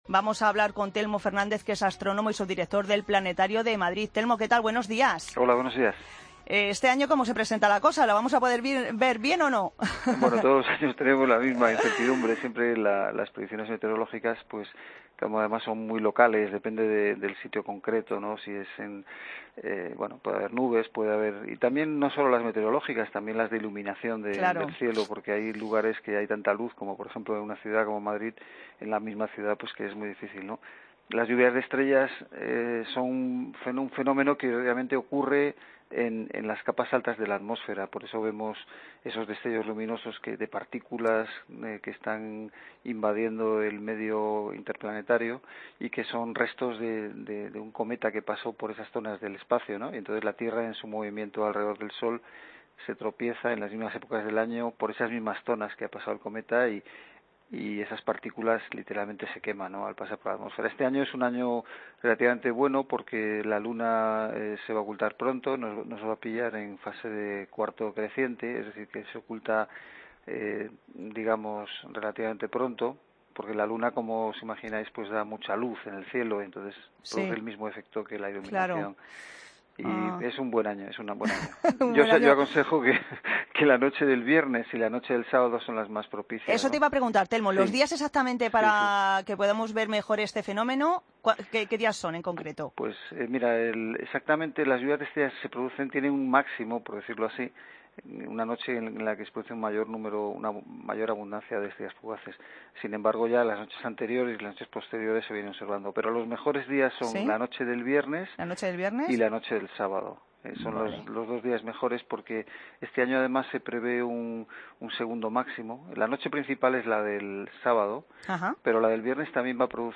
Mediodía COPE Madrid Entrevista